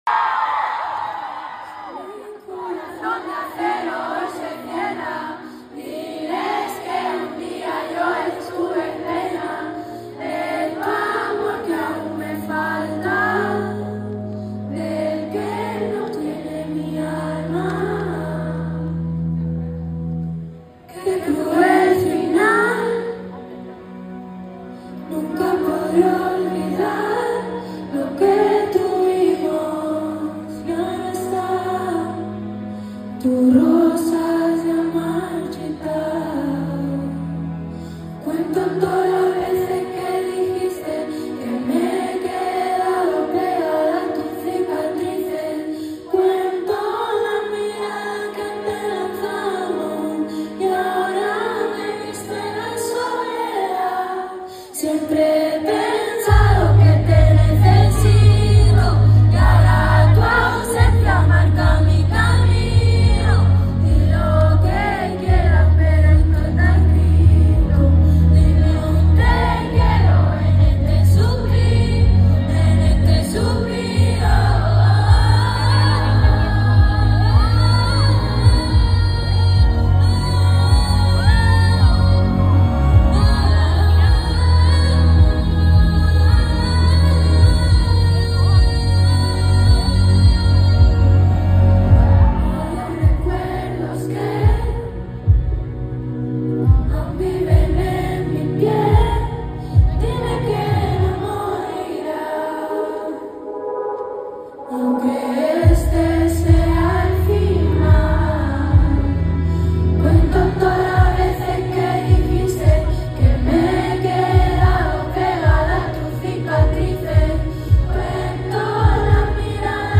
en Madrid